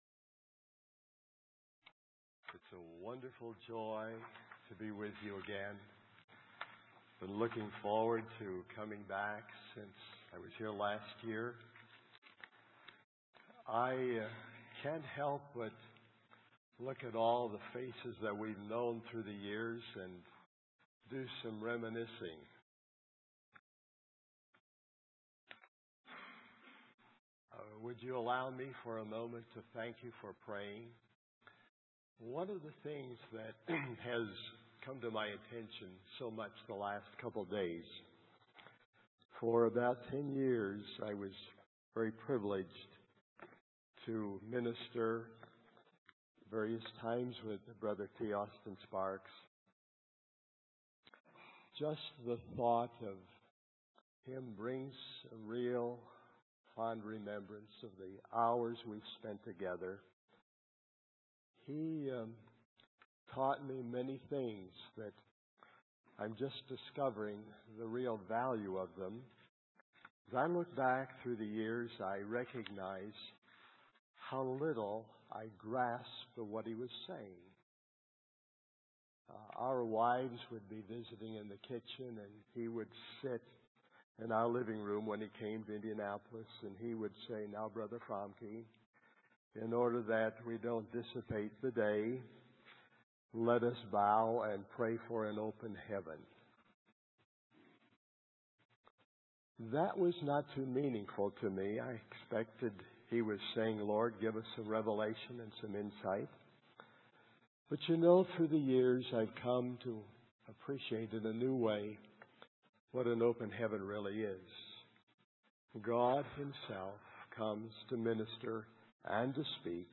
In this sermon, the preacher focuses on the theme of serving one another and how God often uses natural examples to teach us spiritual lessons.